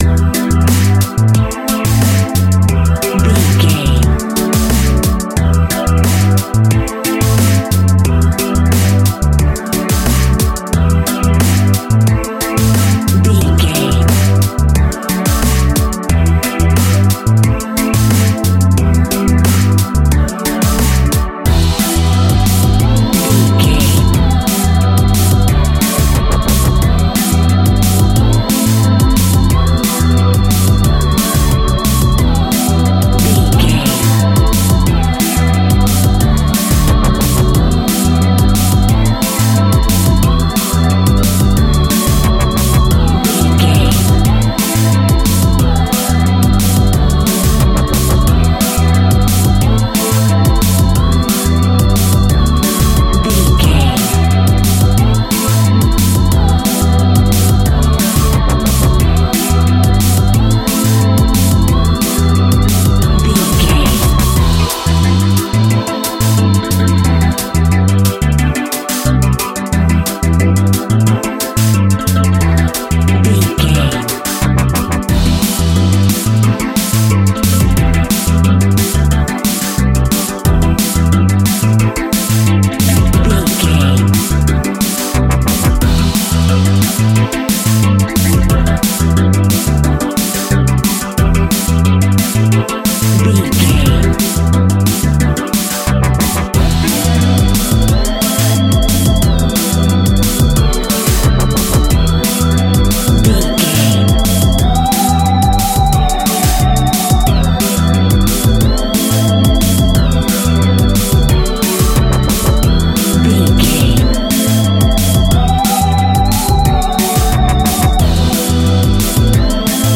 Aeolian/Minor
Fast
futuristic
frantic
energetic
uplifting
hypnotic
industrial
drum machine
synthesiser
bass guitar
Drum and bass
electronic
instrumentals
break beat music
synth bass
synth lead
synth pad
robotic